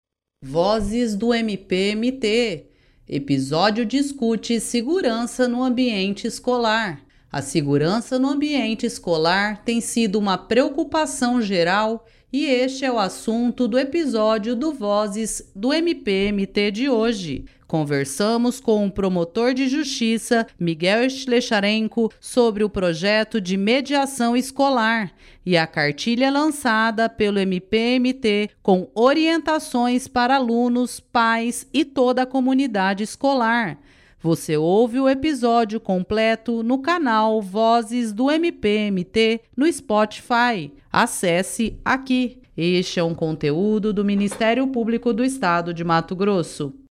A segurança no ambiente escolar tem sido uma preocupação geral e este é o assunto do episódio do Vozes do MPMT de hoje. Conversamos com o promotor de Justiça Miguel Slhessarenko sobre o projeto de Mediação Escolar e a cartilha lançada pelo MPMT com orientações para alunos, pais e toda a comunidade escolar.